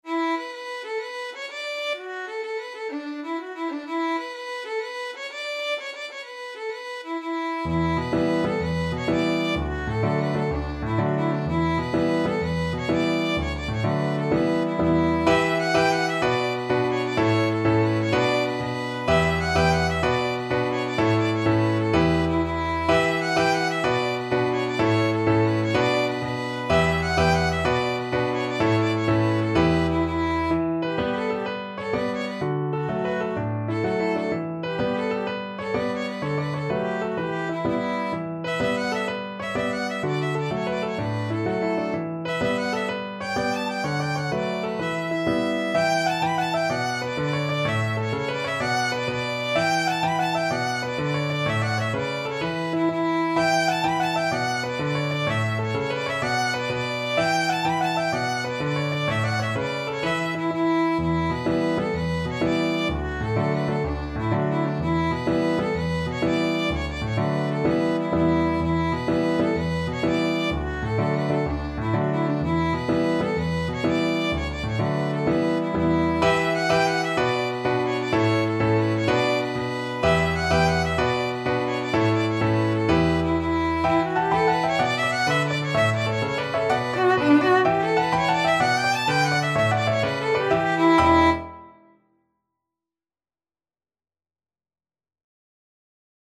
12/8 (View more 12/8 Music)
Fast .=c.126
Classical (View more Classical Violin Music)
Irish